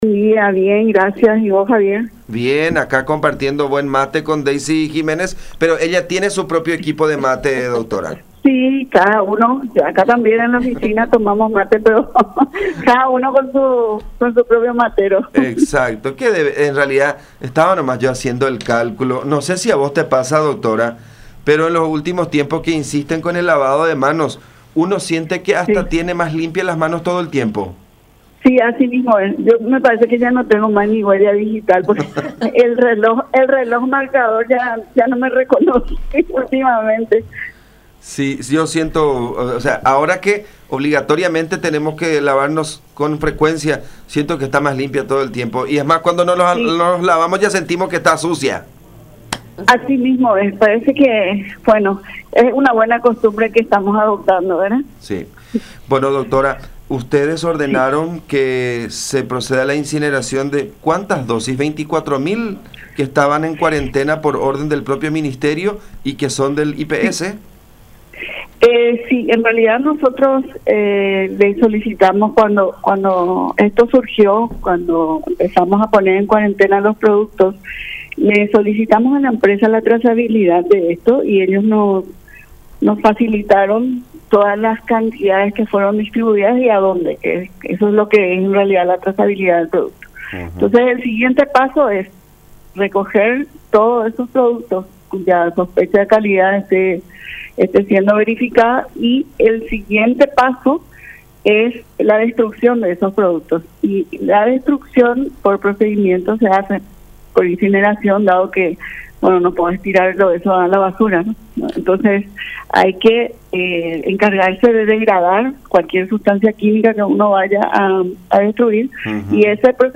El dato fue confirmado por María Antonieta Gamarra, directora de DINAVISA, en contacto con La Unión, quien detalló que en estos momentos está abierta auditoria para identificar si otras empresas vendieron el mismo fármaco, o si IMEDIC y Eurotec vendieron otros medicamentos en mismas condiciones.